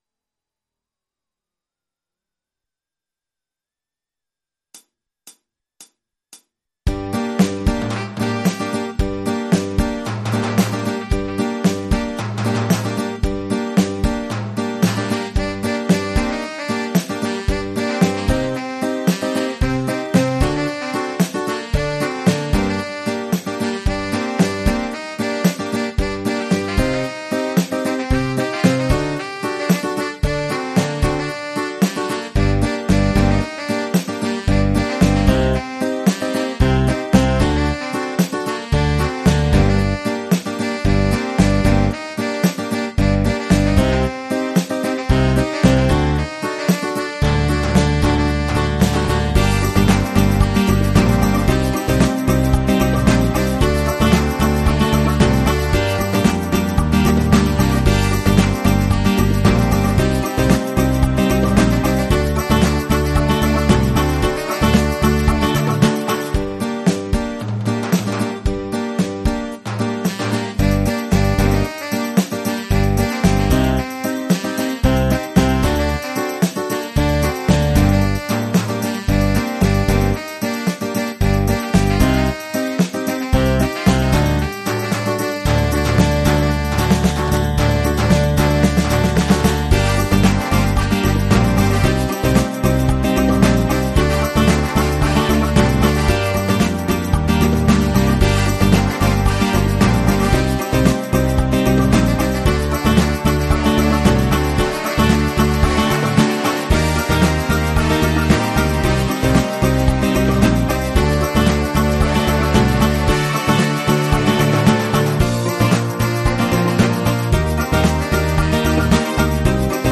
la version instrumentale multipistes